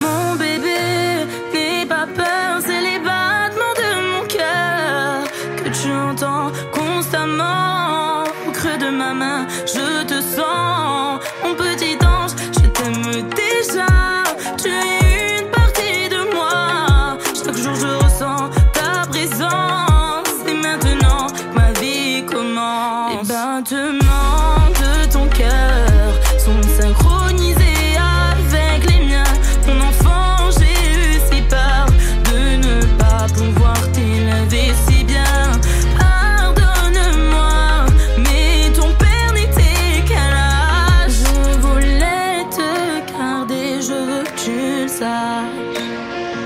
Classique